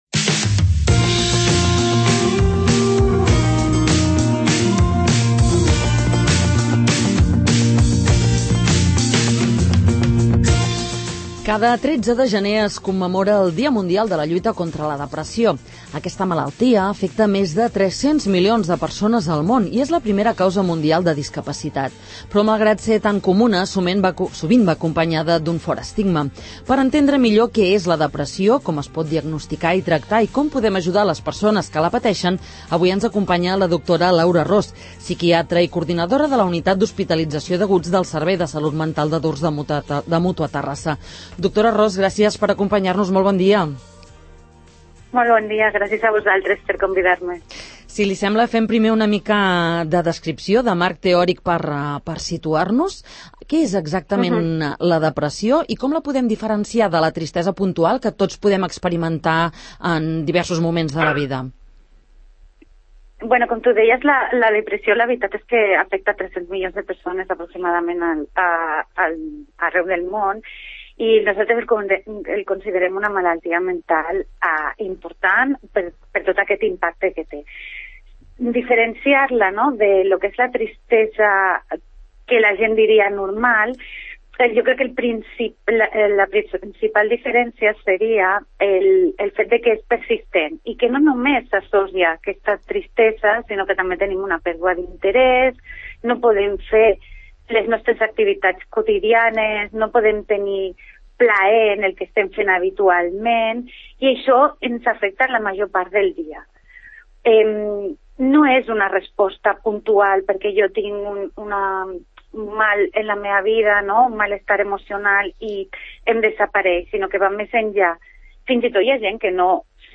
En una entrevista al magazín ‘Faves comptades’